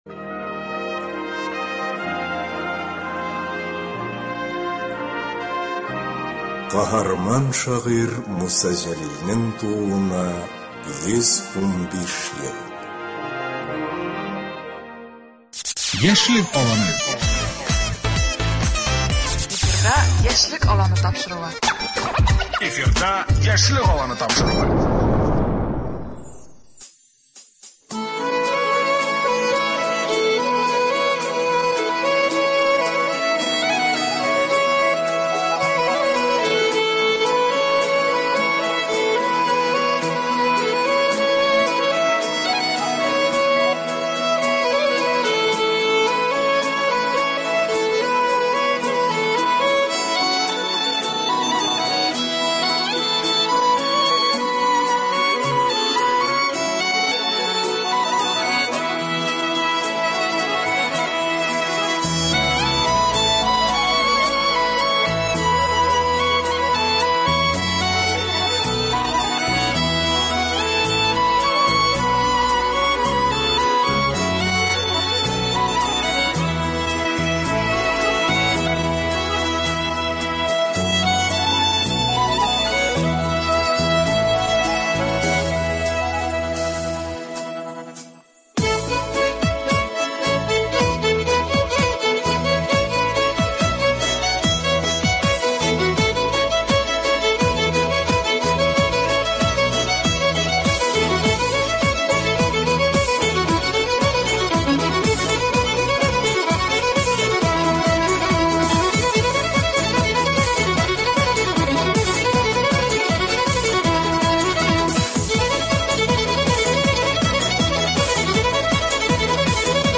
Бүген безнең студиядә кунакта скрипкада халкыбыз көйләрен яңгыратучы